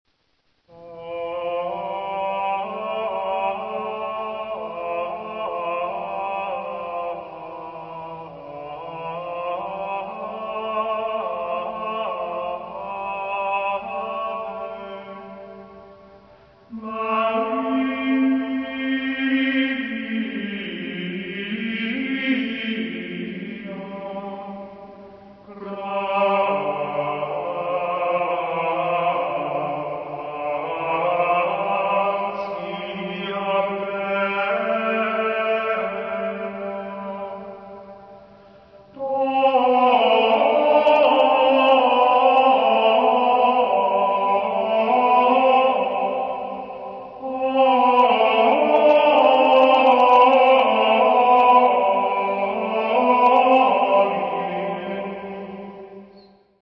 Gregoriaans
daarentegen zijn composities waarin meerdere noten op één lettergreep voorkomen.
Ave Maria Melismatisch